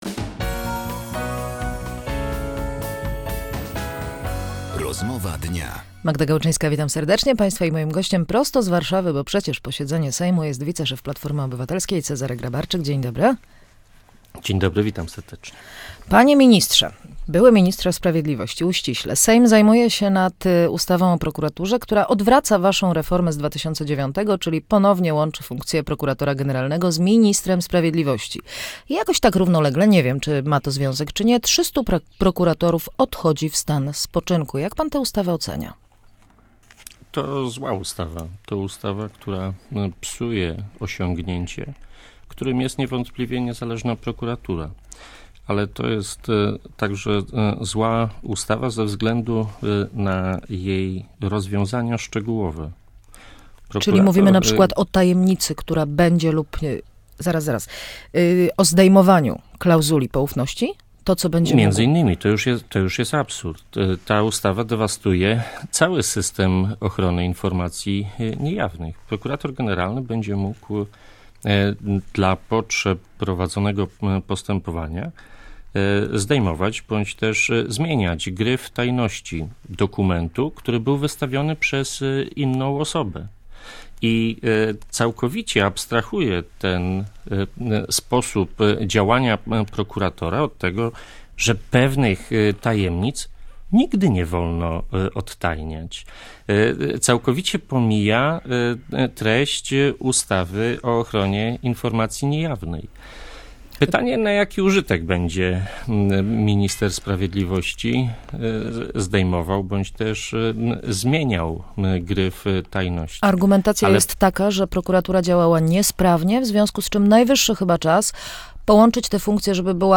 – W Polsce sytuacja jest taka, jak w stanie wojennym – jednoznacznie stwierdził na antenie Radia Łódź wiceszef Platformy Obywatelskiej Cezary Grabarczyk.